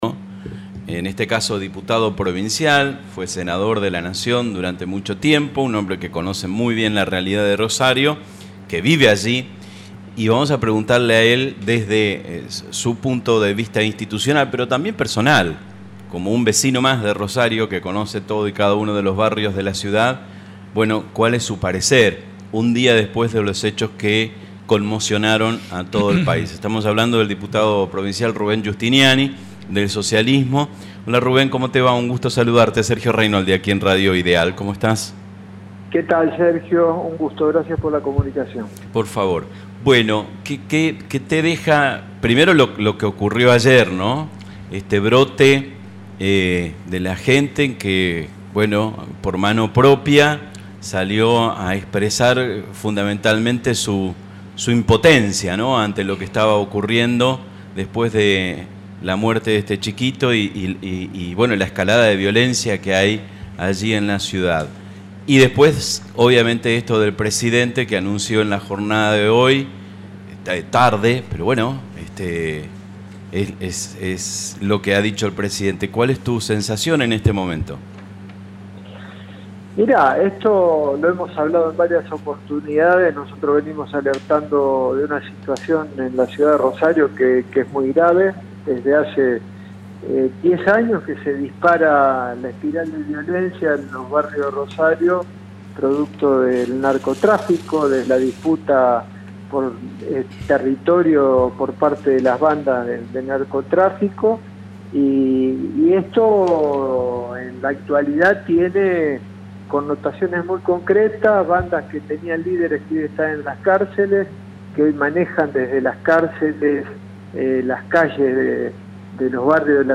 La entrevista completa con Rubén Giustiniani: